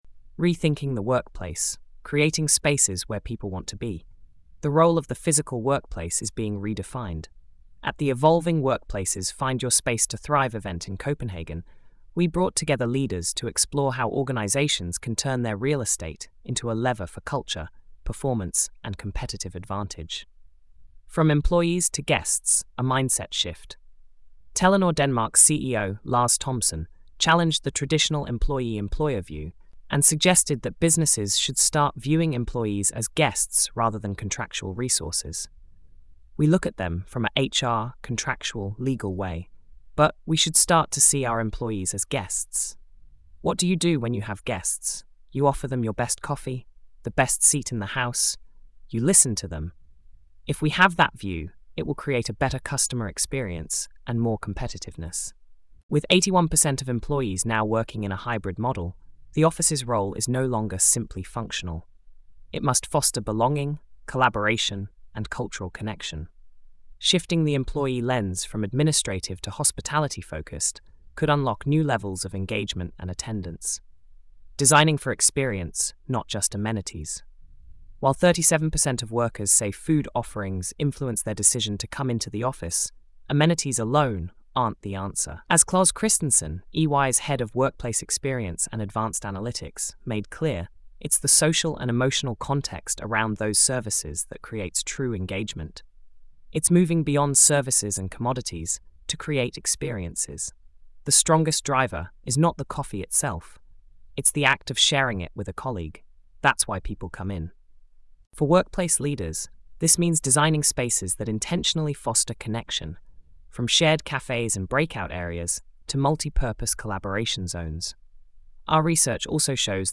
Panel Discussion - audio file.mp3